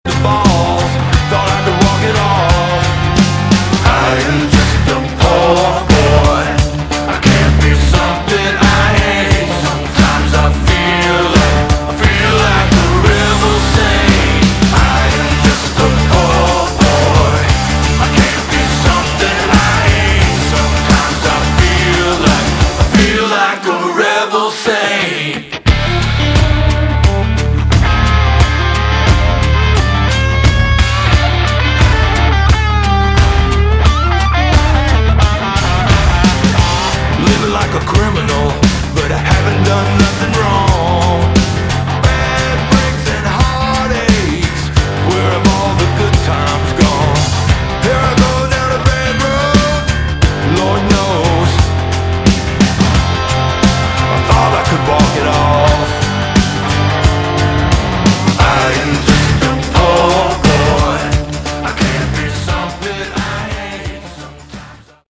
Category: Hard Rock / Boogie Rock
lead vocals, guitar
lead guitars